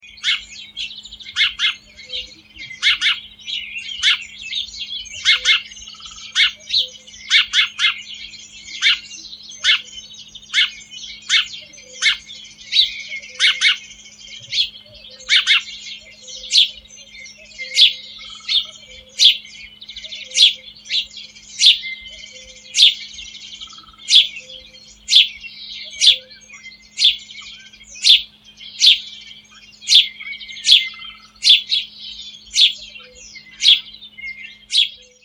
Звуки животных - Воробей (sparrow)
Отличного качества, без посторонних шумов.
996_vorobey.mp3